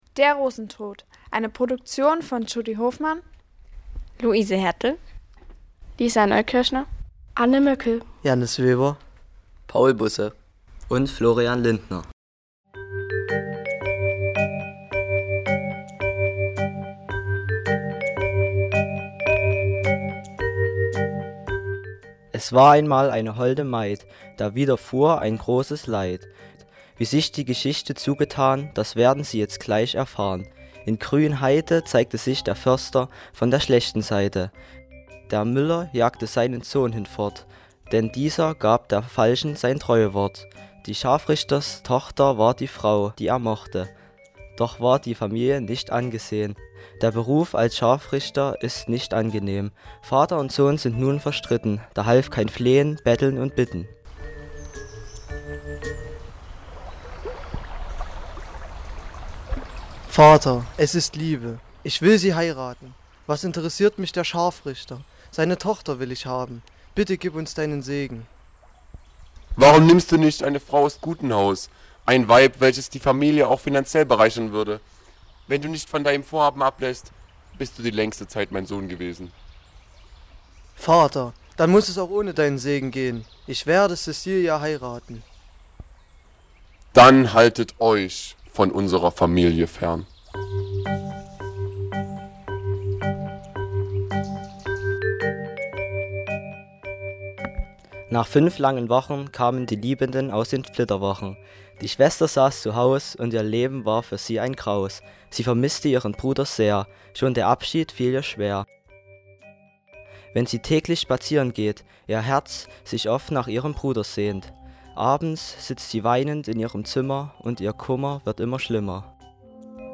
Hörspiel-Der-Rosentod.mp3